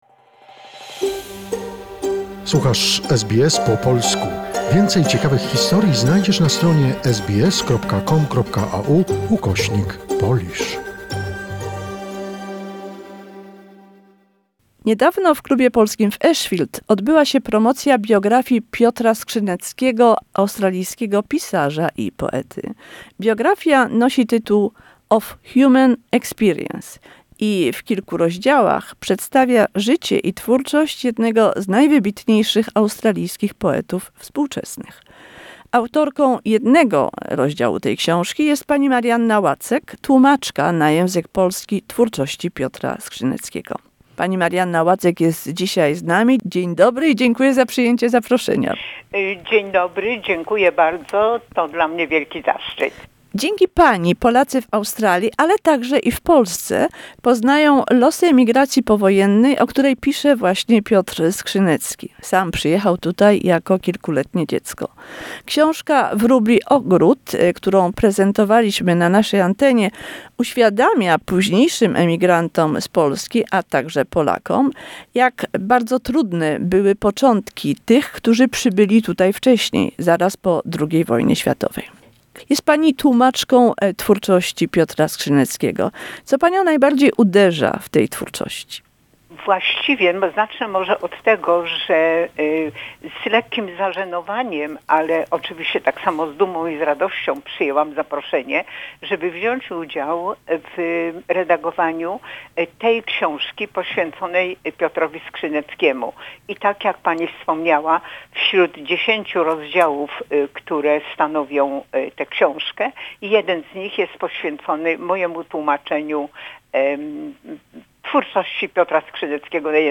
The essence of post-war emigration contained in the books and poetry of Piotr Skrzynecki. Interview